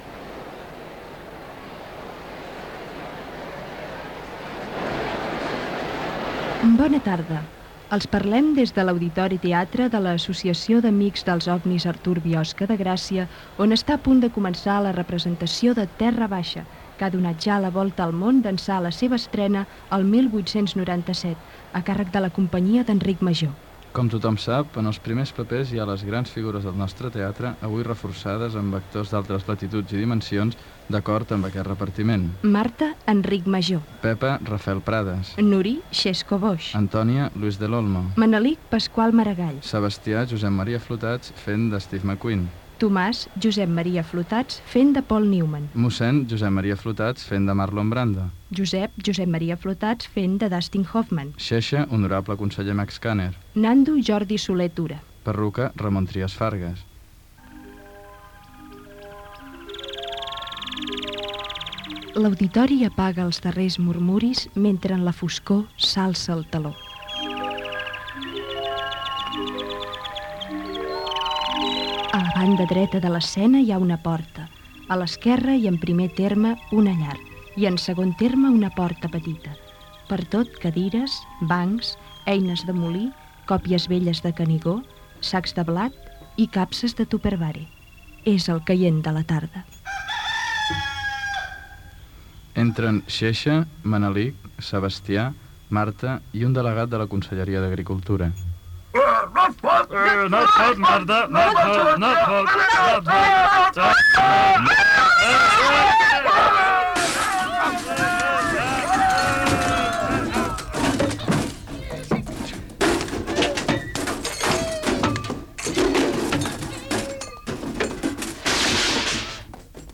Esquetx amb el repartiment i la representació de "Terra Baixa" d'Àngel Guimerà en una entitat de la vila de Gràcia
Cultura